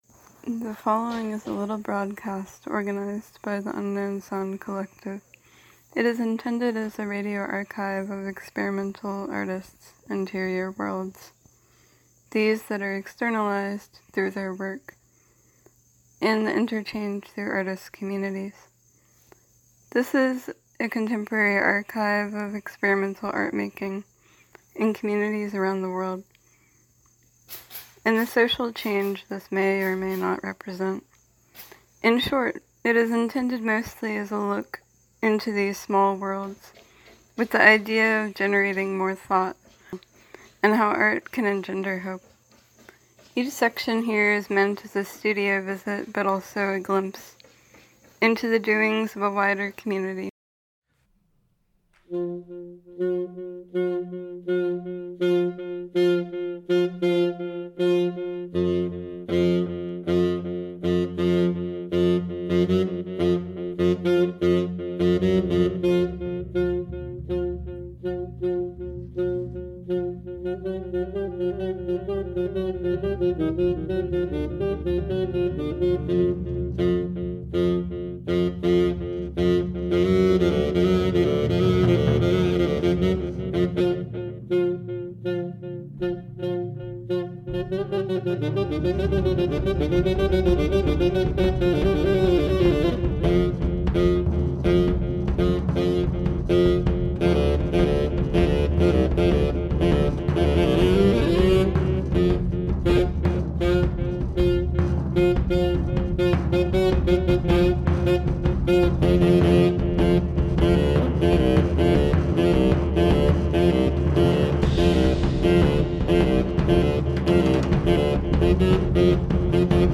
dedicated to unexpected sounds and art